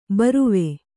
♪ baruve